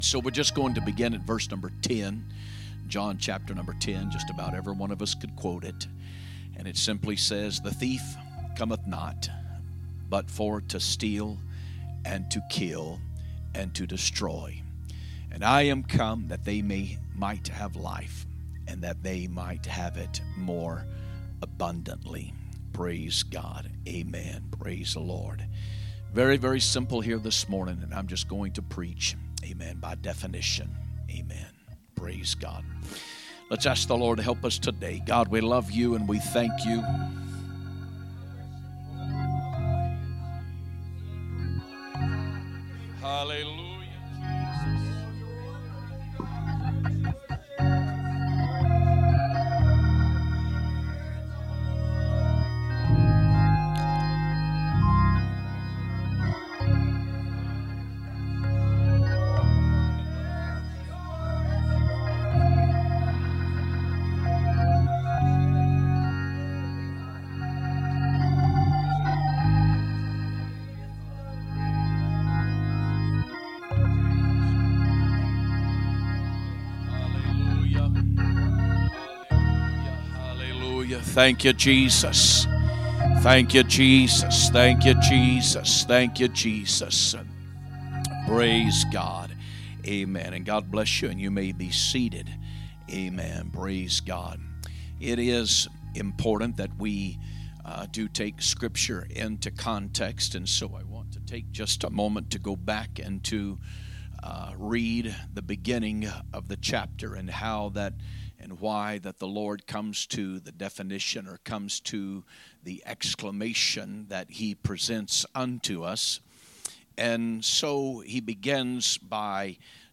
4/6/2025 Sunday Morning Service